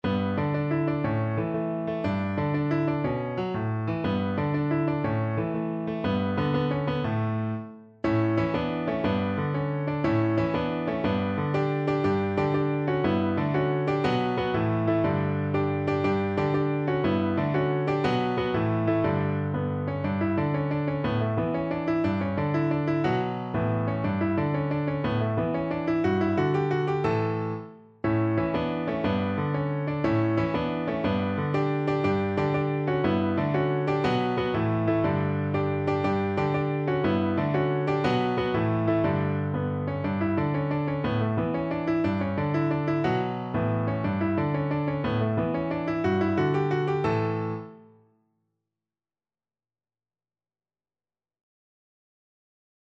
Play (or use space bar on your keyboard) Pause Music Playalong - Piano Accompaniment Playalong Band Accompaniment not yet available transpose reset tempo print settings full screen
6/8 (View more 6/8 Music)
D major (Sounding Pitch) (View more D major Music for Violin )
With energy .=c.120
Classical (View more Classical Violin Music)